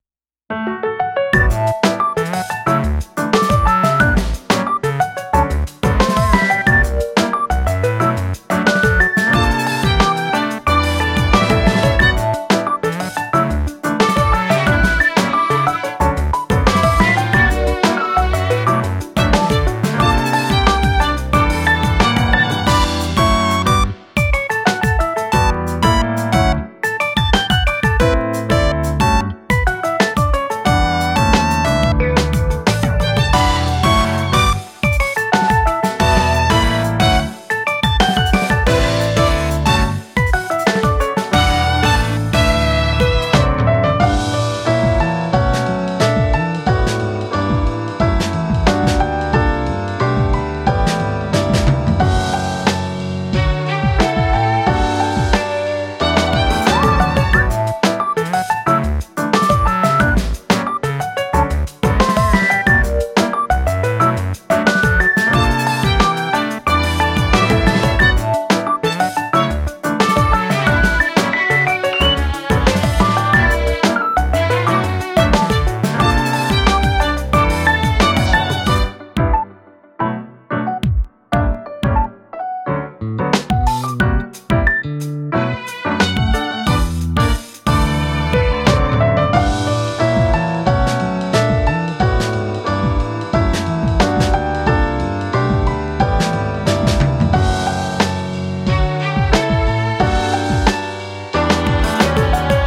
ループ用音源（BPM=90）